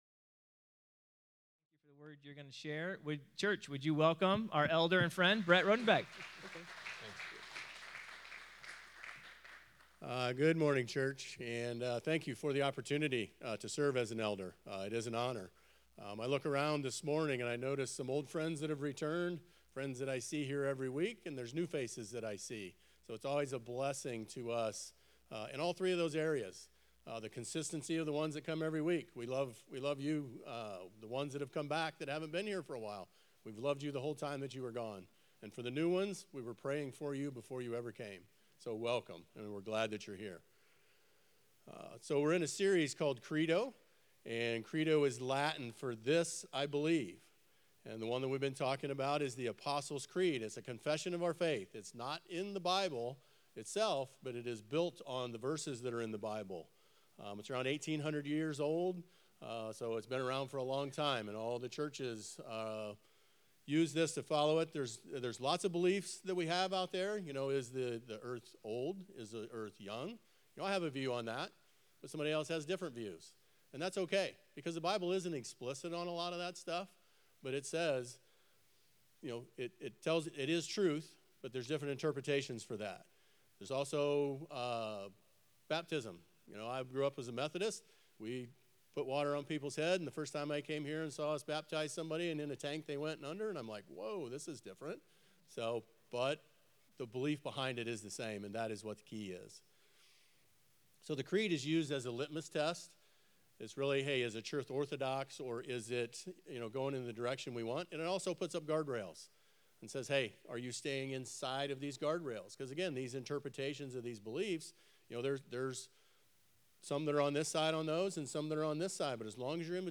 Sermon-Mar-8.mp3